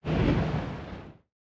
fissure-explosion-1.ogg